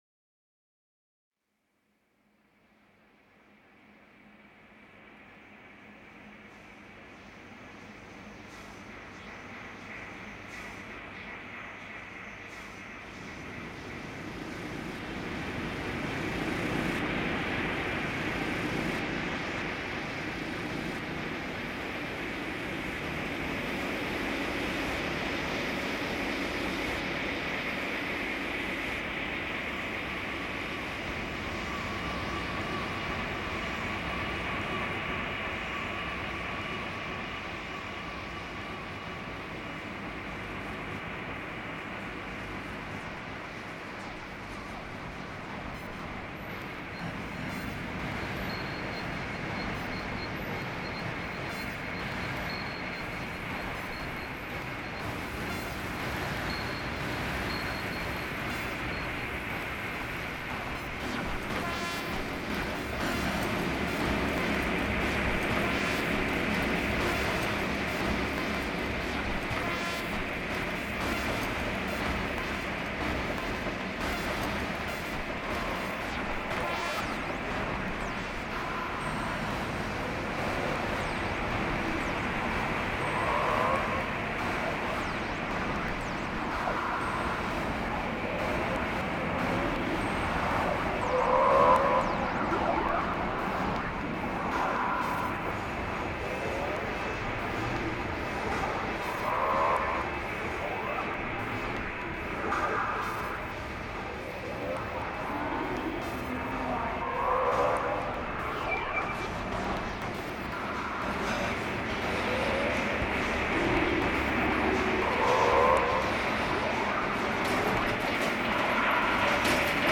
Genre: experimental, electronic.